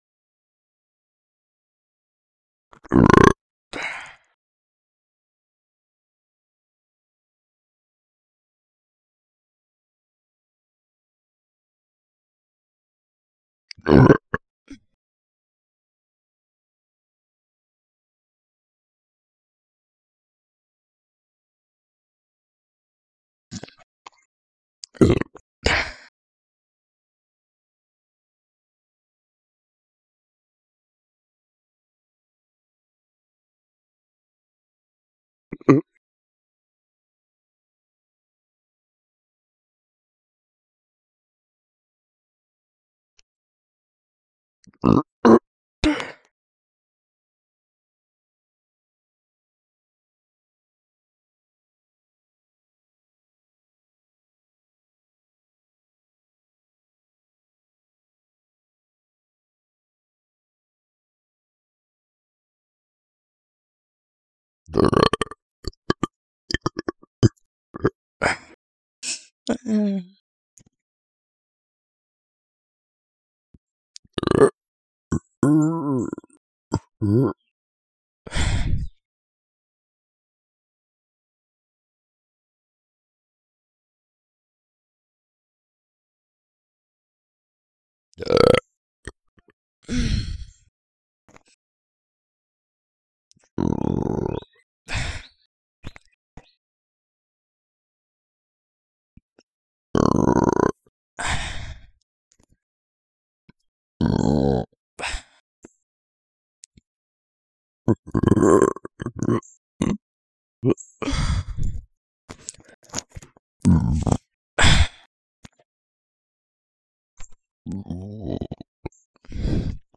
Assorted Burping Sounds
air belch burp deep disgusting female funny gross sound effect free sound royalty free Funny